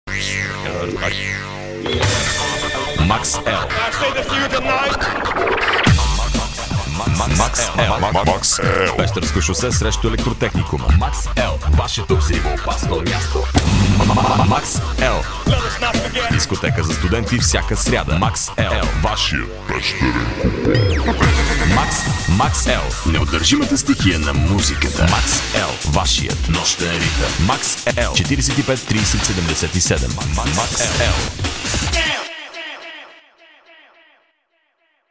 Max-L radio commercial spot